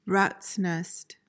PRONUNCIATION: (RATS nest) MEANING: noun: A confused mess.